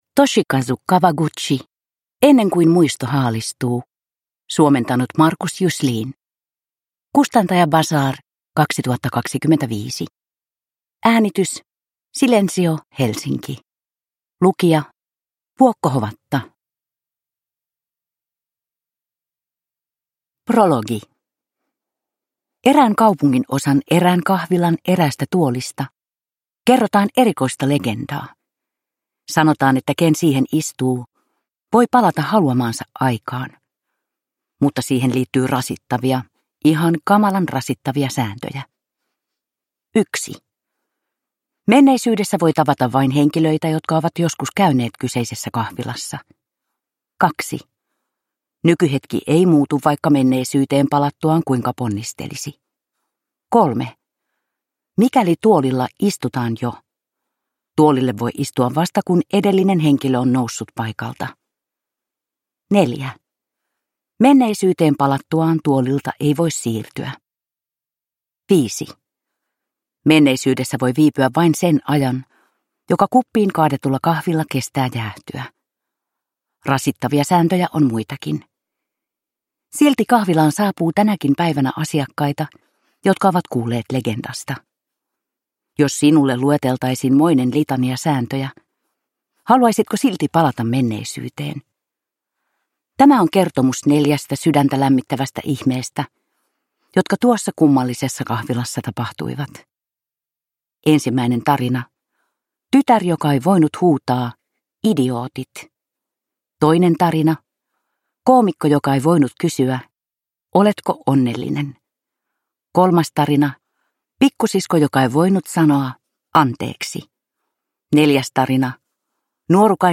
Ennen kuin muisto haalistuu – Ljudbok